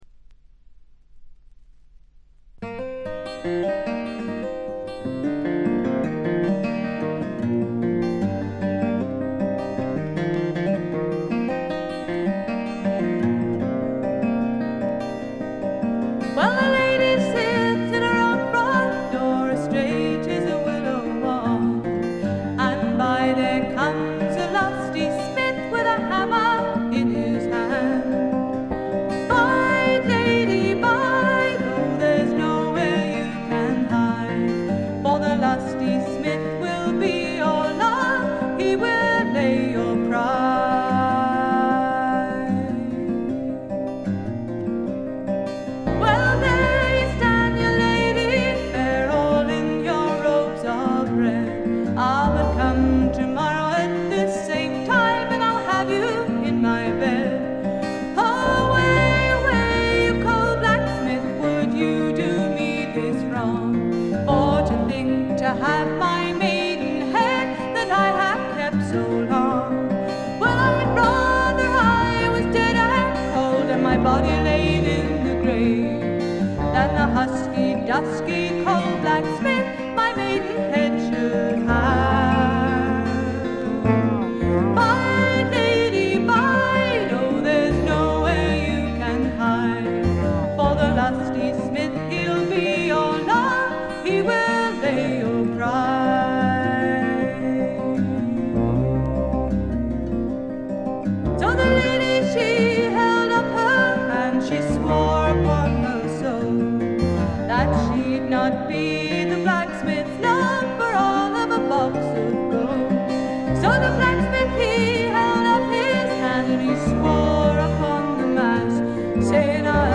ごくわずかなノイズ感のみ。
試聴曲は現品からの取り込み音源です。
Bass, Synth
Fiddle